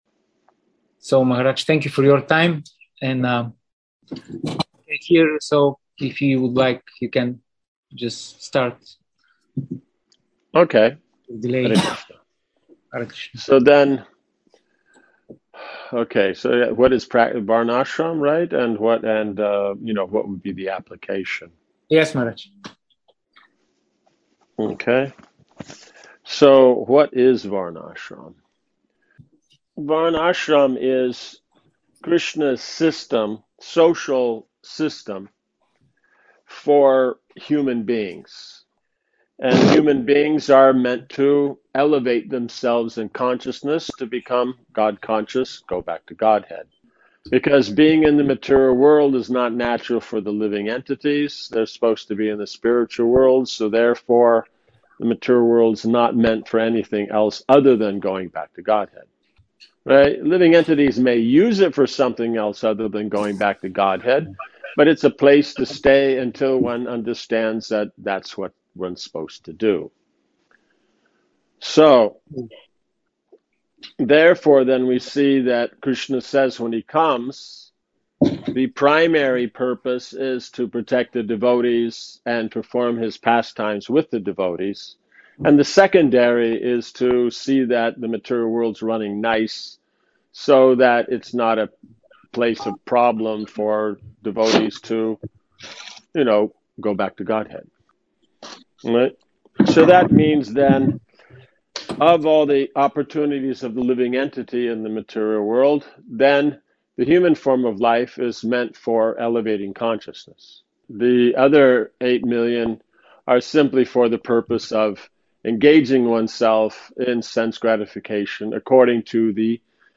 Varṇāśrama Seminar Bulgaria
Seminar on Varṇāśrama with Bulgarian devotees